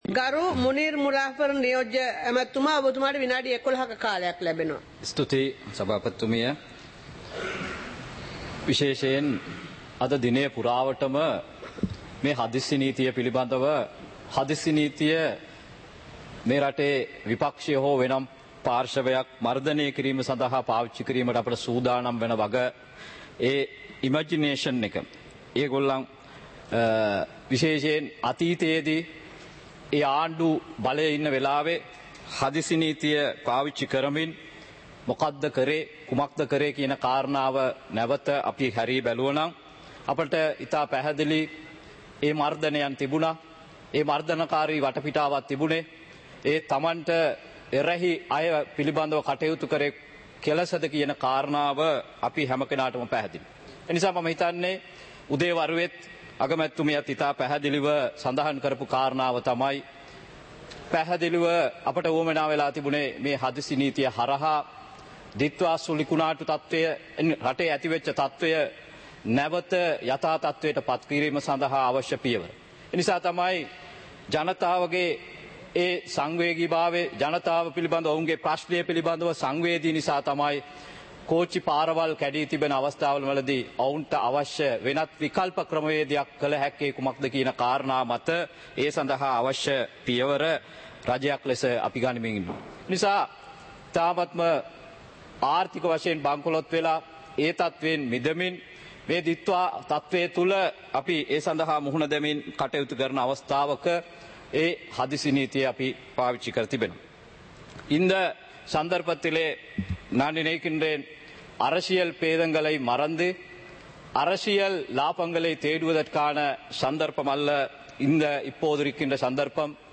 Proceedings of the House (2026-03-06)
Parliament Live - Recorded